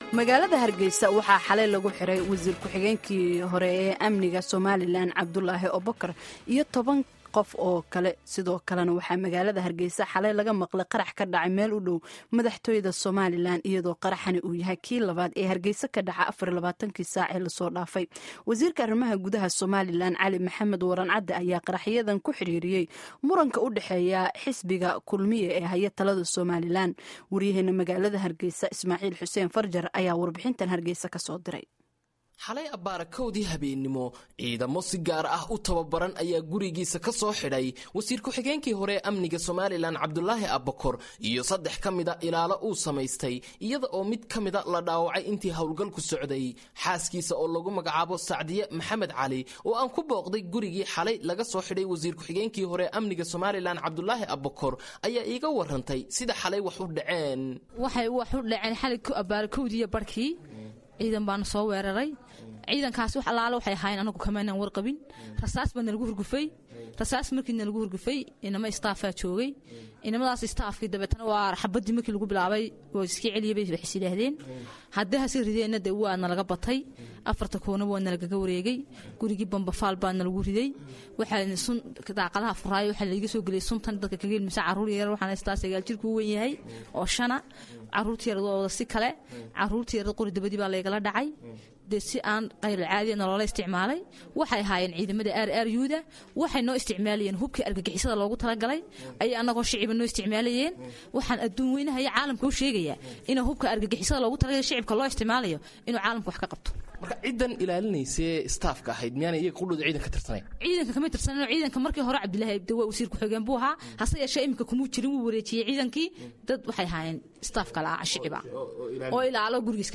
Dhageyso warbixin iyo wareysiga guddoomiyaha Guurtida